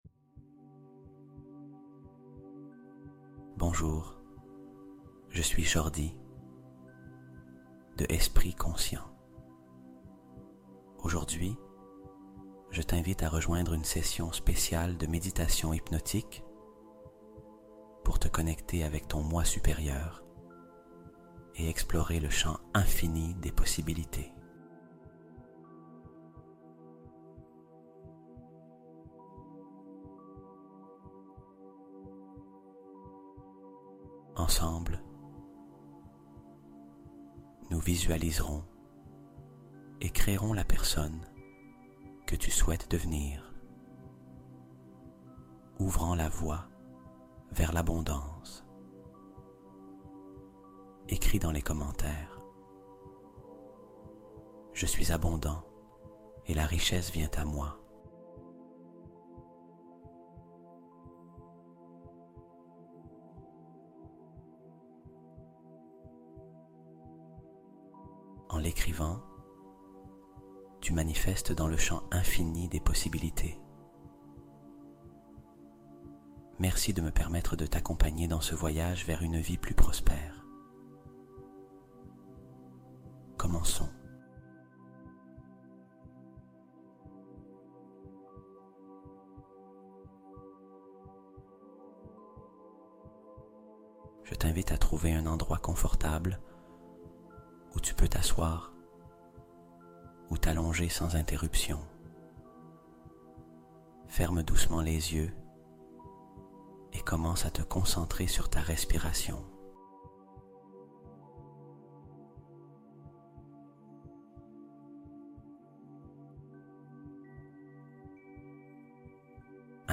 DEVIENS RICHE PENDANT QUE TU DORS | Hypnose Du Moi Supérieur Qui Reprogramme Ton Rapport À L'Argent